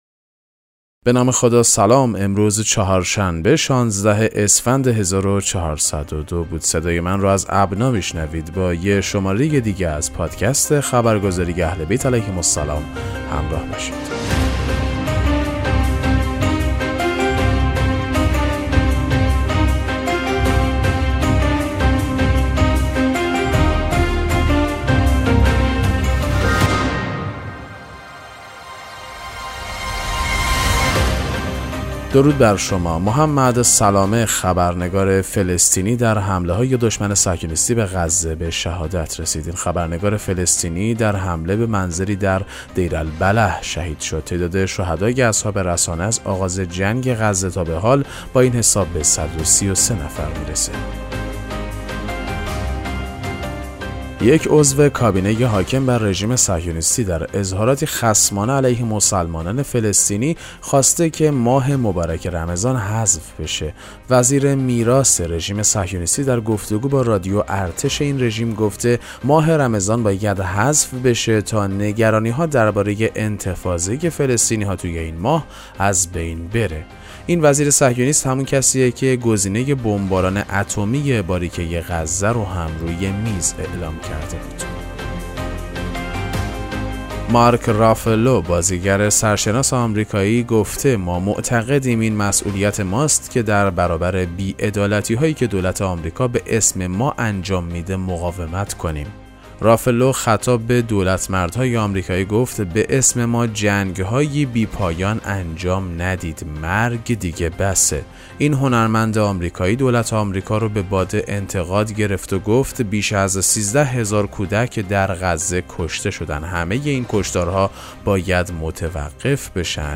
پادکست مهم‌ترین اخبار ابنا فارسی ــ 16 اسفند 1402